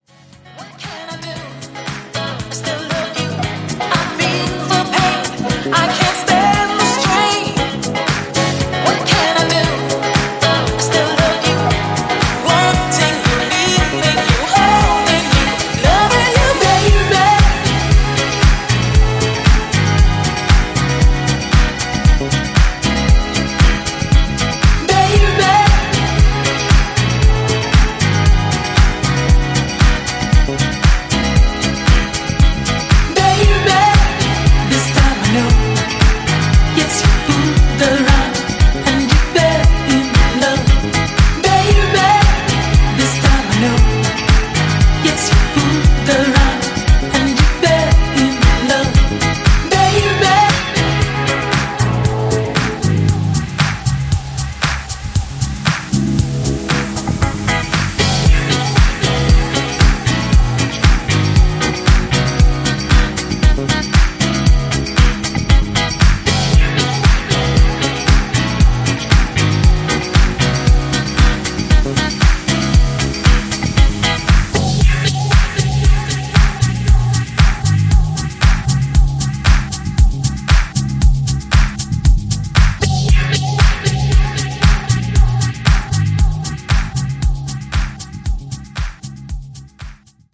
soul classics remixed for the floor